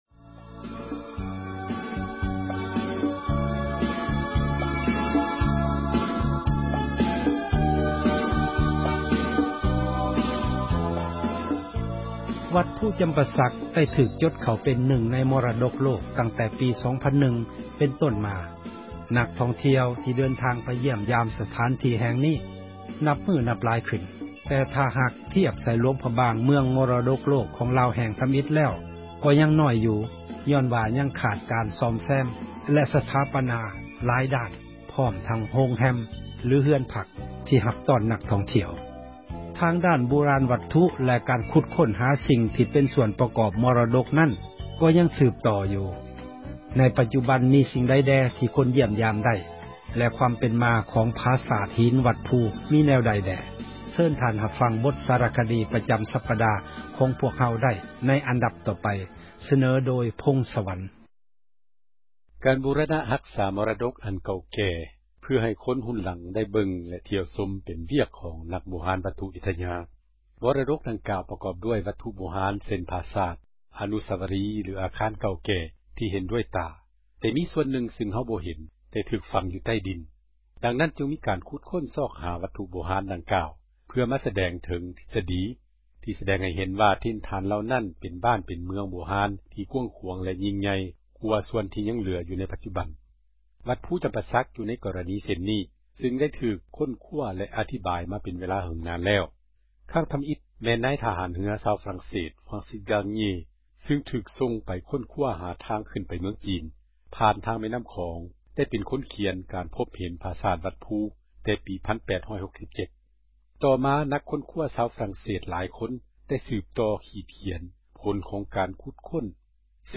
ໃນປັດຈຸບັນ ມີສິ່ງໃດແດ່ ທີ່ຄົນທ່ຽວຊົມ ຢ້ຽມຢາມໄດ້ ແລະ ຄວາມເປັນມາຂອງ ຜາສາດຫີນ ວັດພູ ມີແນວໃດ? ເຊີນທ່ານ ຮັບຟັງ ບົດສາຣະຄະດີ ປະຈຳສັປດາ ຂອງພວກເຮົາໄດ້...